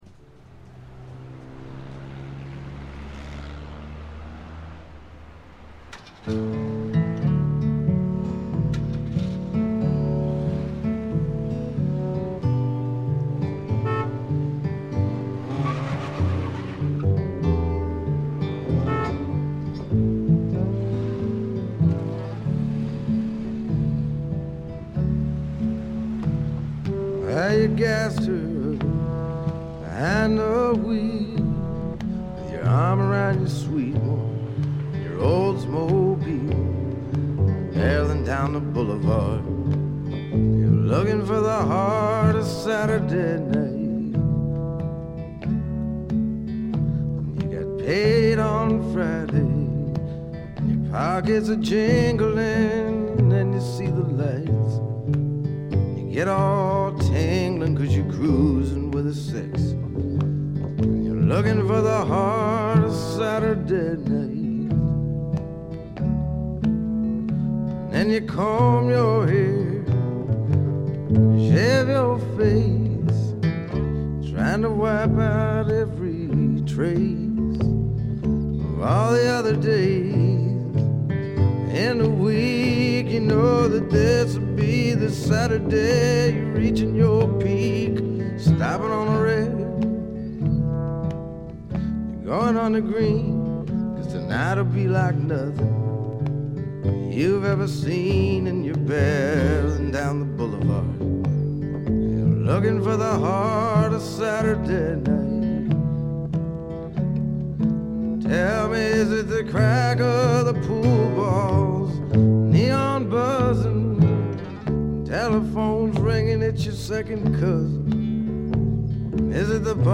軽微なチリプチ程度。
メランコリックでぞっとするほど美しい、初期の名作中の名作です。
試聴曲は現品からの取り込み音源です。
vocals, piano, guitar